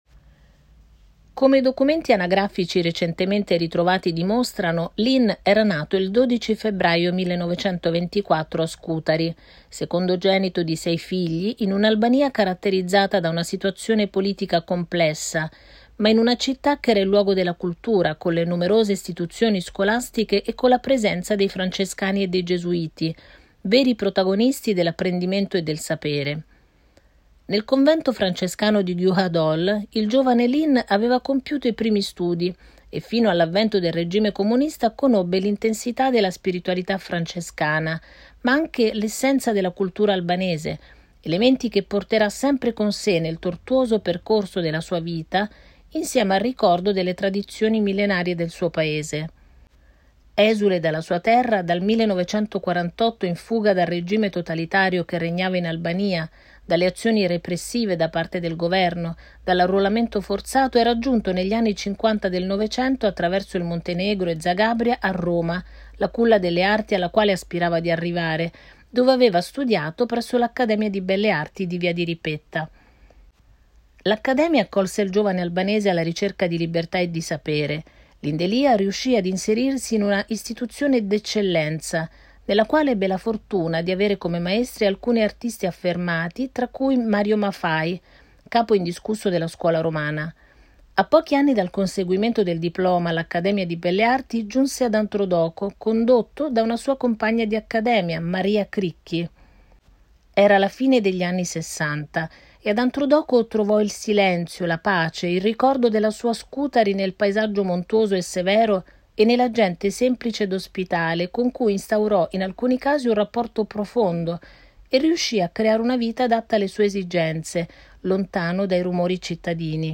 Testo Audioguide